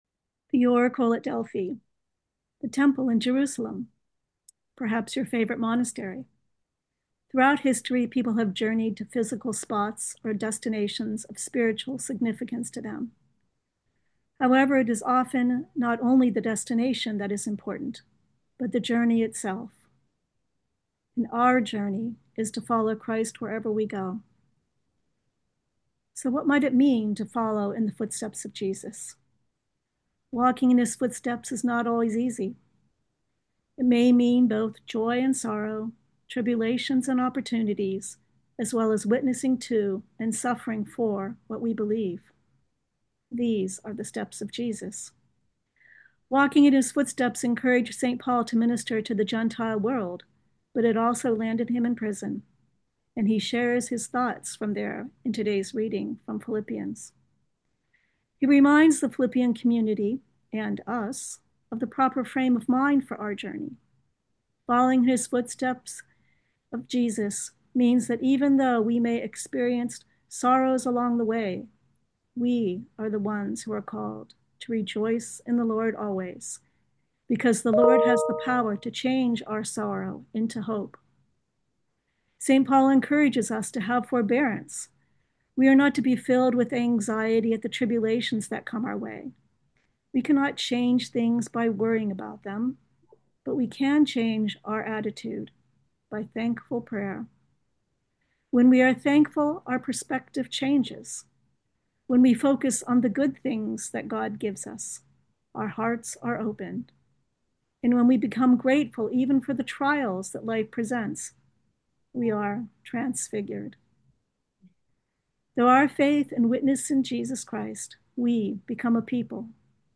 New Year’s Thanksgiving Prayer Service & Fellowship Zoom Event Reflections